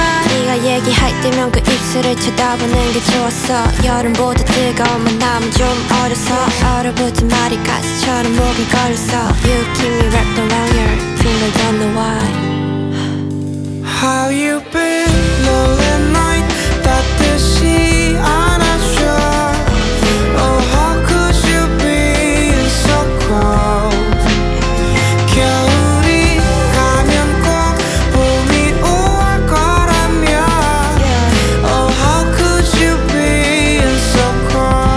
K-Pop Pop Hip-Hop Rap Korean Hip-Hop